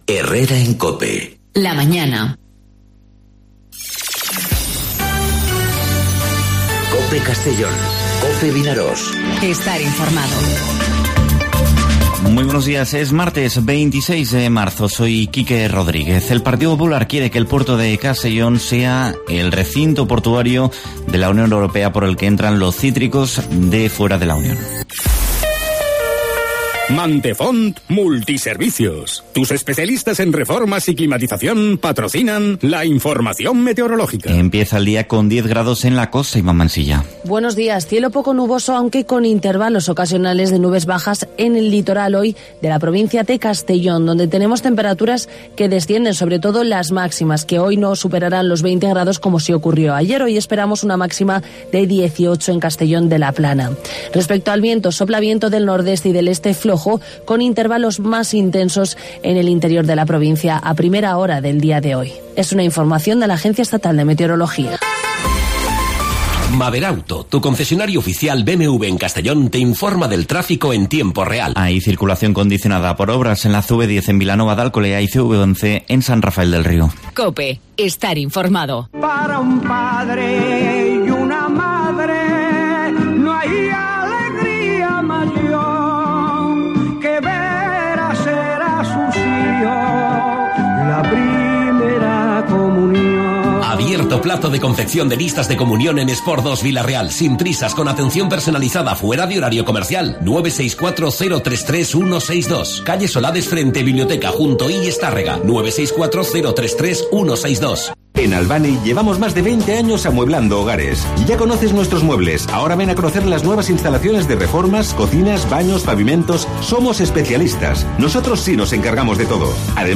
Informativo 'Herrera en COPE' Castellón (26/03/2019)